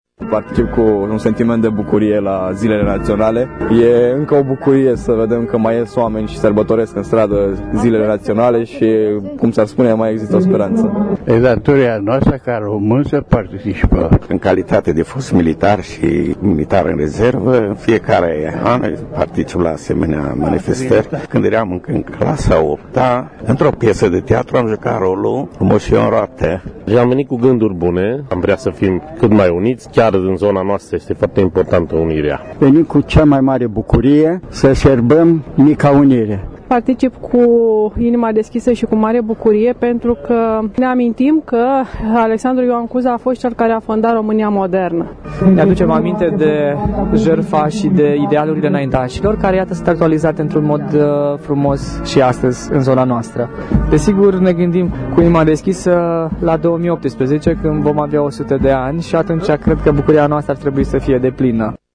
Aniversarea a 158 de ani de la Unirea Moldovei cu Ţara Românească a fost sărbătorită marţi la Sfântu Gheorghe de aproximativ 100 de persoane, care, în ciuda frigului, au asistat  la ceremoniile oficiale, la parada militară şi au dansat Hora Unirii în jurul statuii voievodului Mihai Viteazul:
Vox-unire-CV.mp3